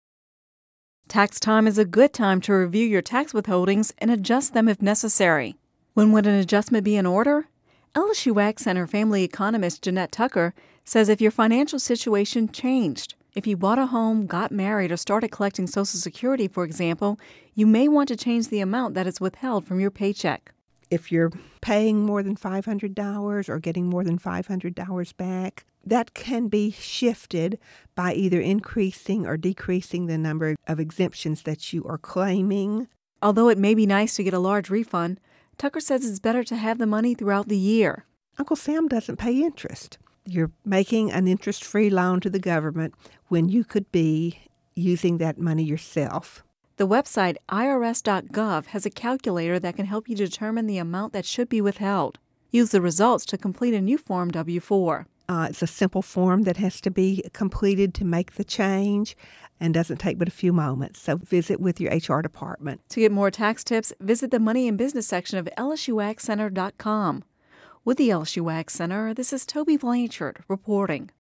(Radio News 04/07/11) Tax time is a good time to review your tax withholdings and adjust them, if necessary.